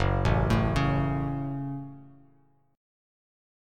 Gbm Chord
Listen to Gbm strummed